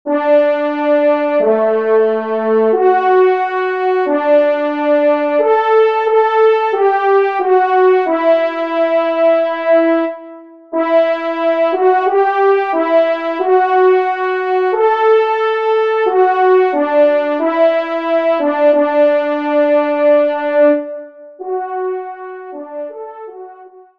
Genre : Musique Religieuse pour Trois Trompes ou Cors
Pupitre 1°Trompe